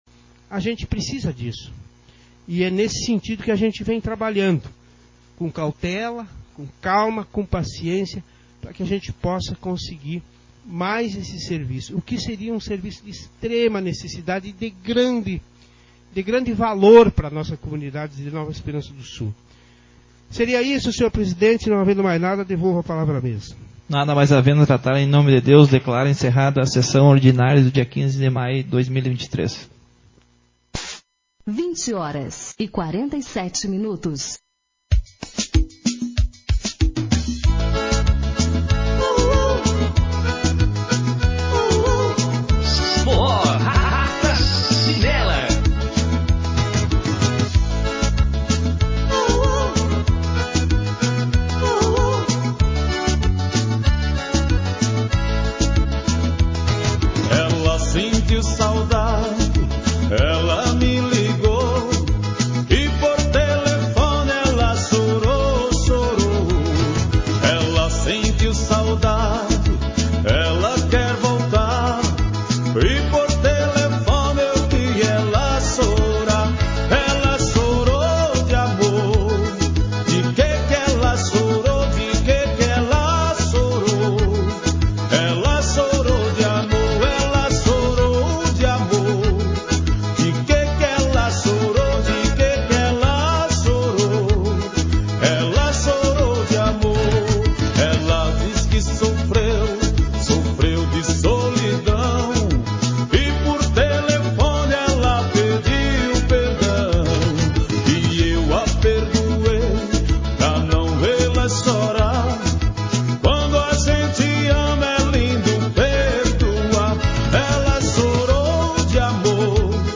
Sessão Ordinária 14/2023